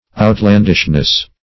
outlandishness \out*land"ish*ness\ n.